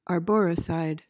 PRONUNCIATION:
(ar-BO-ruh-syd)